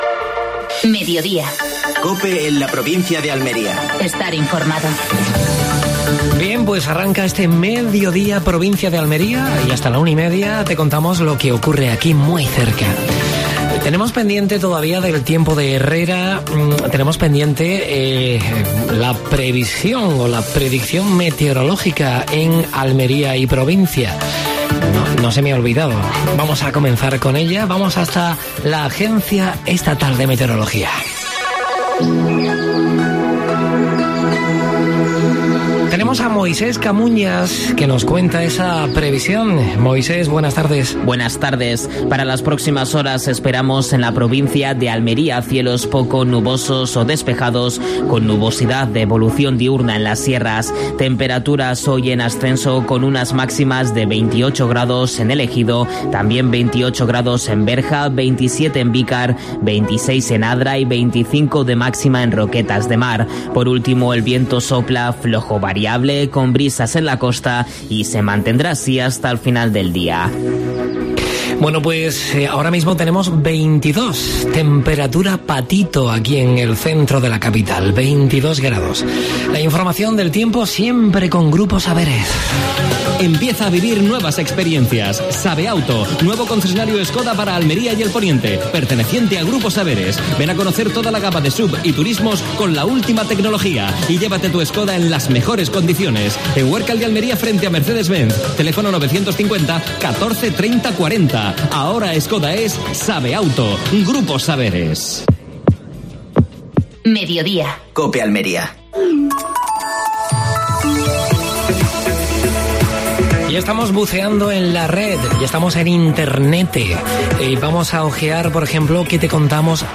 AUDIO: Entrevista al diputado provincial de Fomento, Óscar Liria.